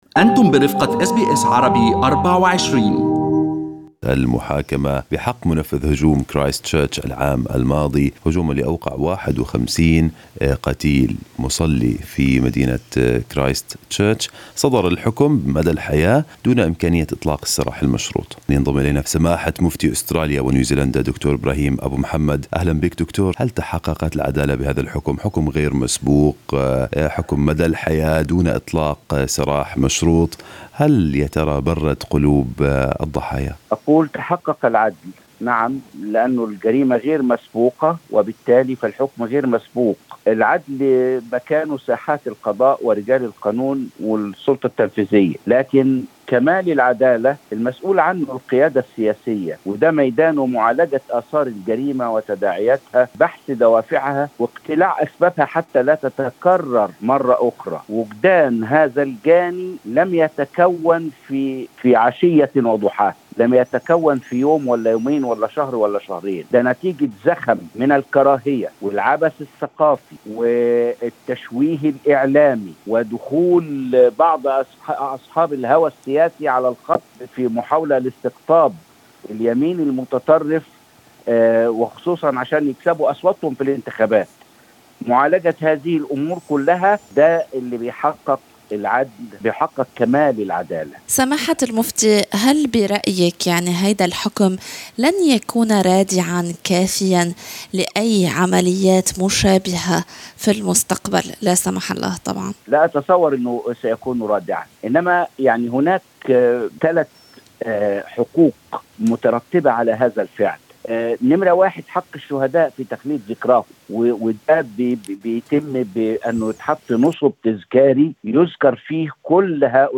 استمعوا إلى المقابلة مع مفتي عام القارة الأسترالية د. ابراهيم أبو محمد في الملف الصوتي المرفق بالصورة.